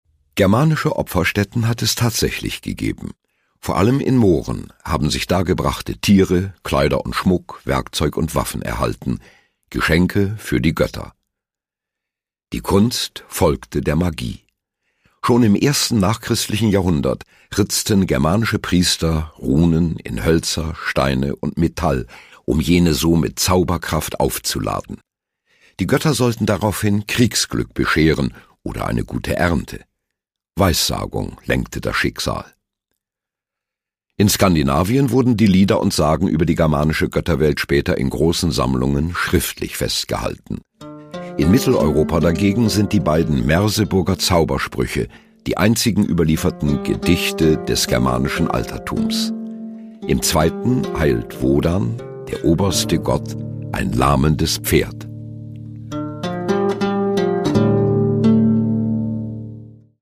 Produkttyp: Hörbuch-Download
Fassung: inszenierte Lesung mit Musik
Gelesen von: Rolf Becker